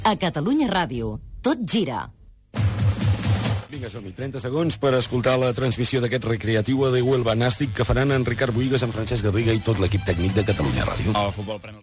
Indicatiu del programa i presentació del partit de futbol a radiar: Recreativo Huelva contra Gimnàstic de Tarragona
Esportiu